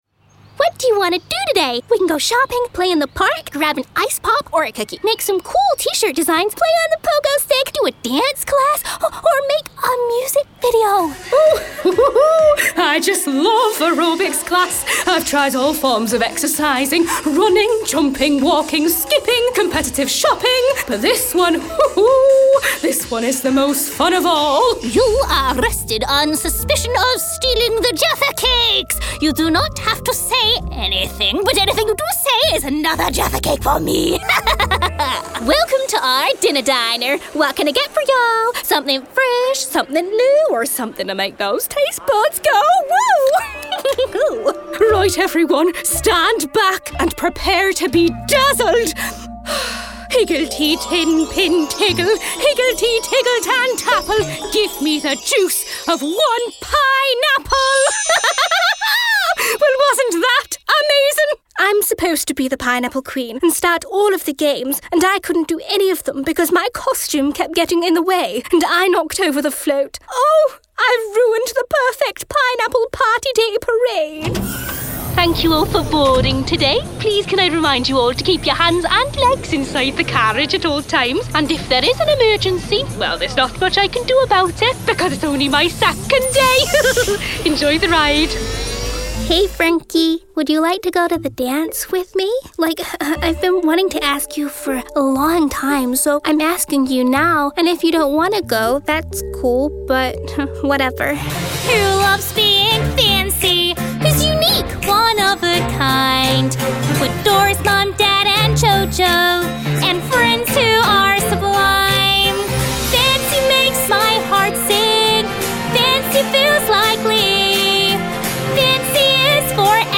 English (British)
Commercial, Young, Cool, Versatile, Friendly